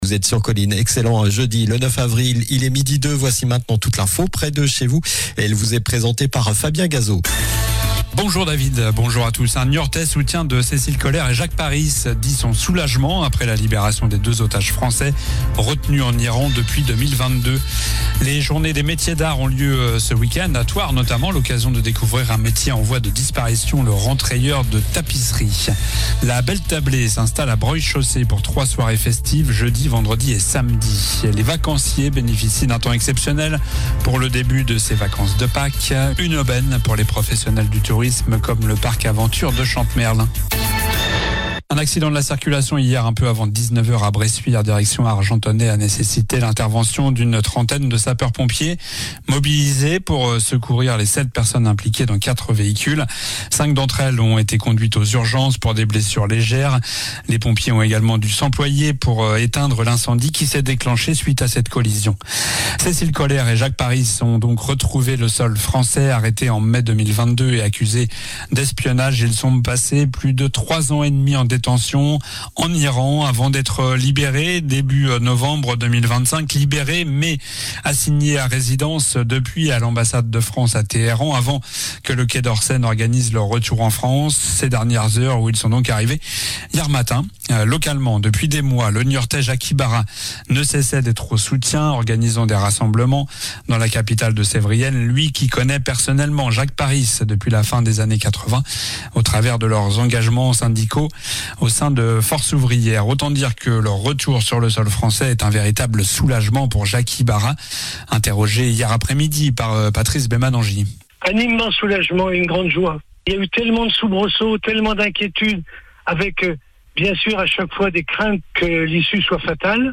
Journal du jeudi 9 avril (midi)